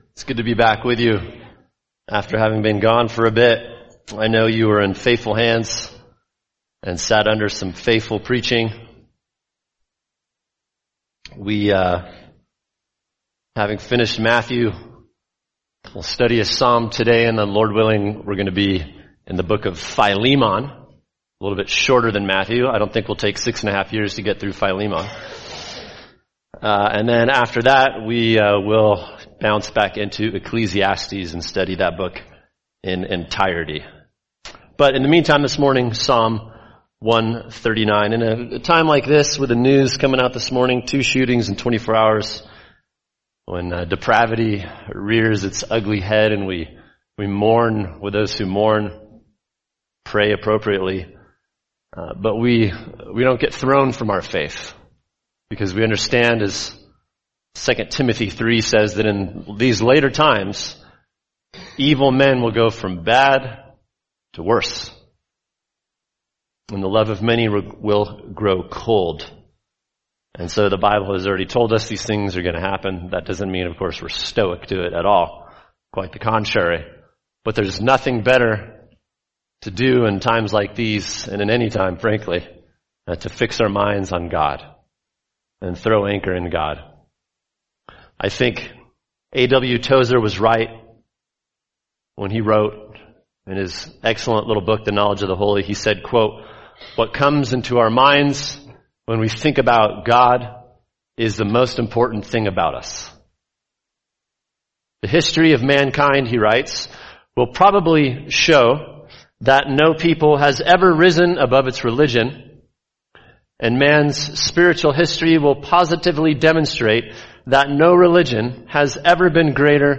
[sermon] Psalm 139 – The Greatness & Nearness of God | Cornerstone Church - Jackson Hole